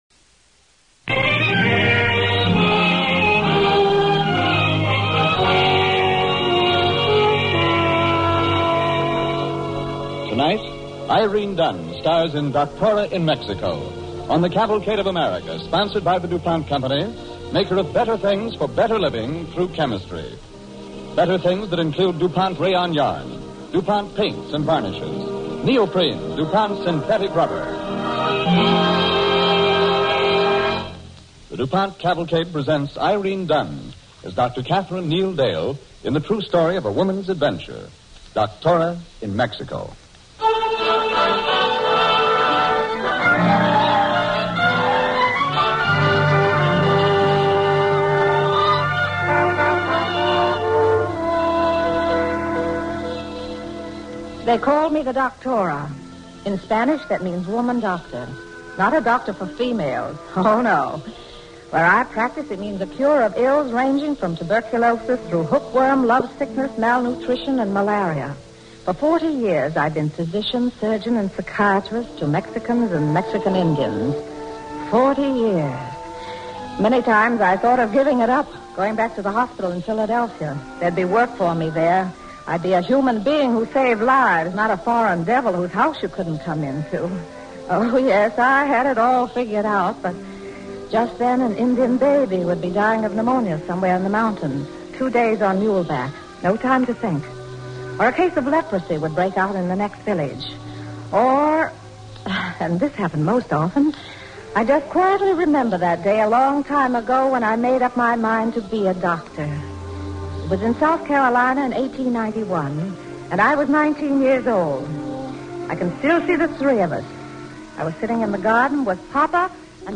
Cavalcade of America Radio Program Doctora in Mexico, starring Irene Dunne and Jay Novello